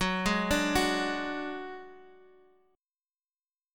Gbsus2#5 Chord